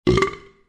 Eructo